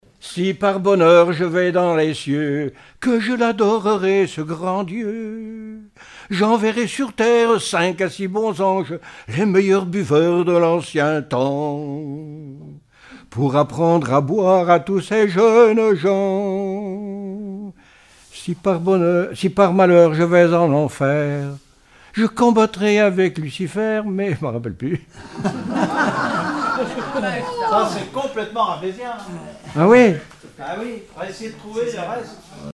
circonstance : bachique
Répertoire de chansons populaires et traditionnelles
Pièce musicale inédite